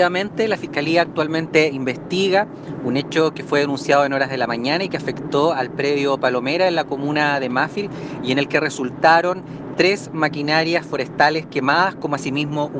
Fiscal Eric Aguayo